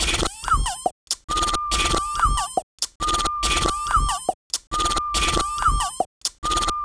NM - Beatbox 140 BPM.wav